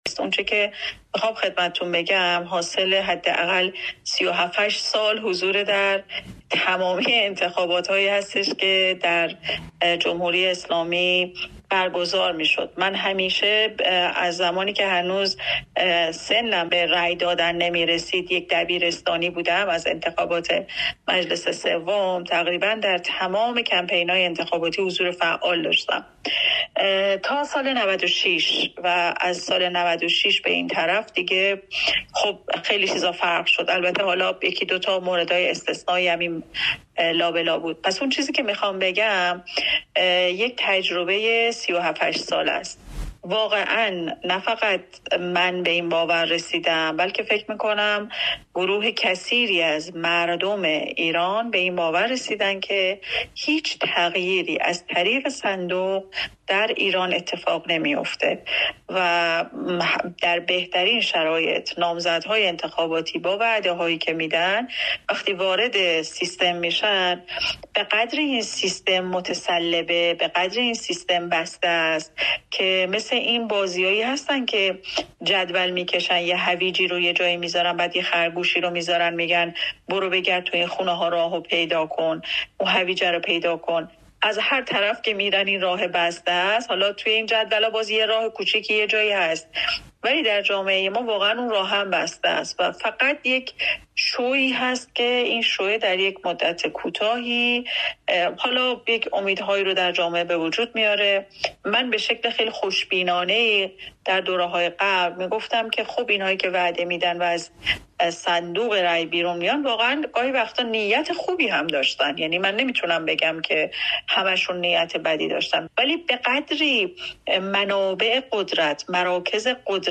او در گفت‌وگو با رادیوفردا دیدگاه خود را تشریح می‌کند.